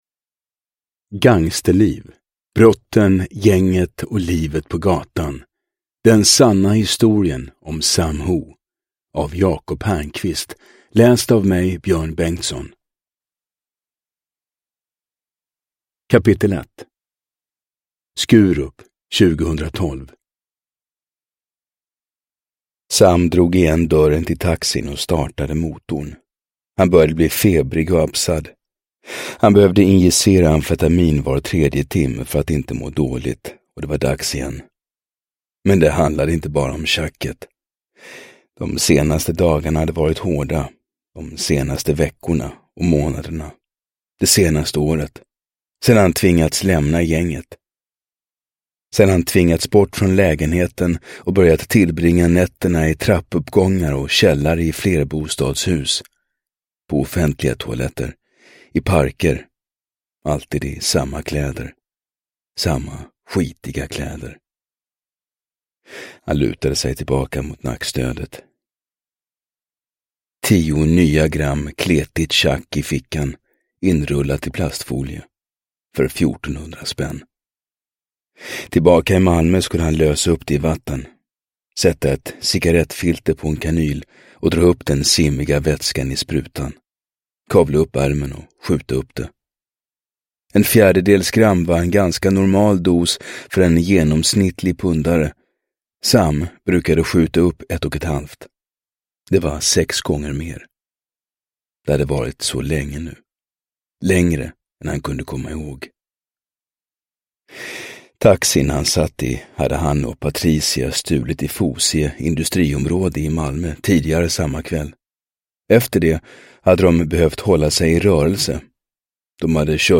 Gangsterliv : Brotten, gänget och livet på gatan – Ljudbok – Laddas ner